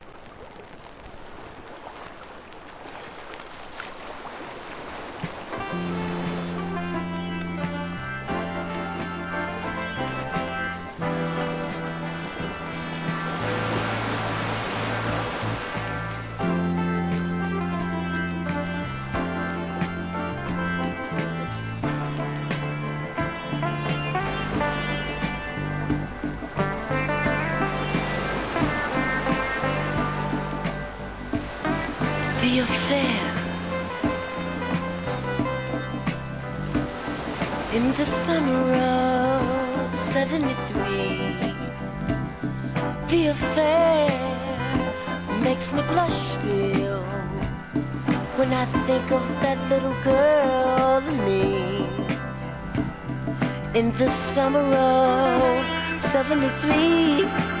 ¥ 1,540 税込 関連カテゴリ SOUL/FUNK/etc...